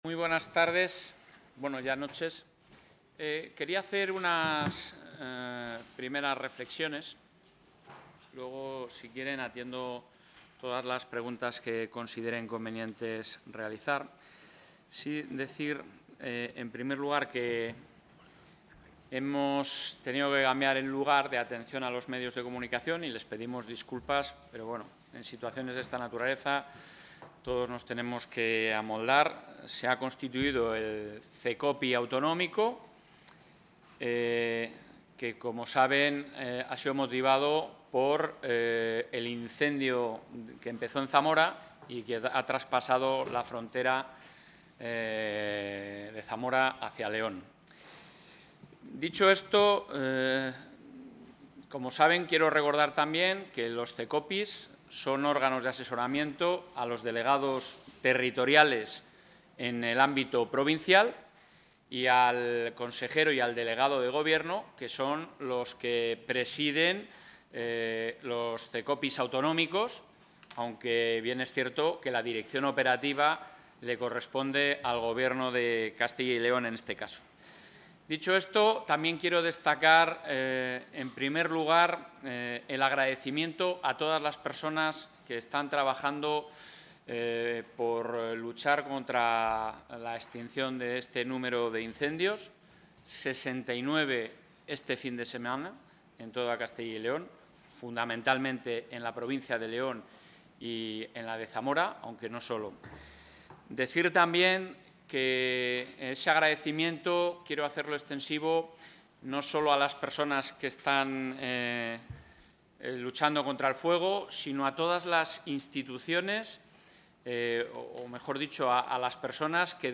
Declaraciones del presidente de la Junta.